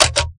Switch2.ogg